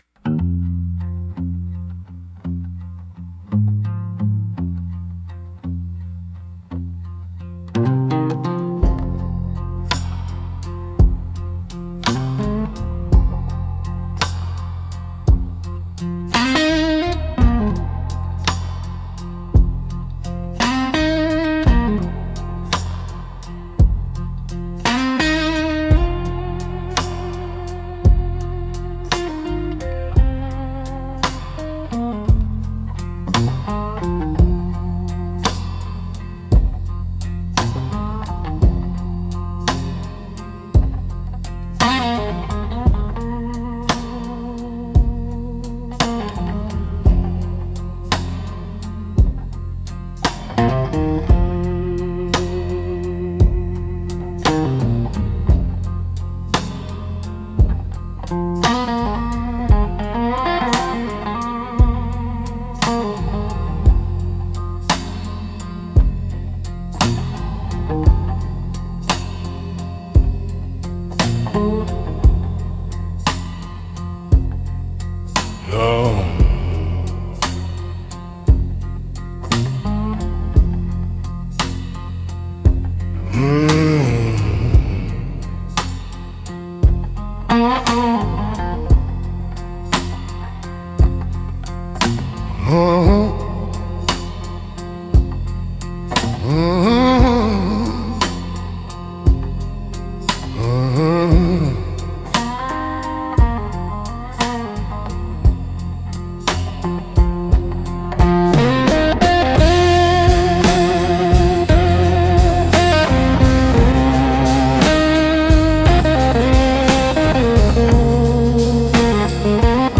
Instrumentals - Swamp Hymn in Open D